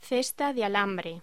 Locución: Cesta de alambre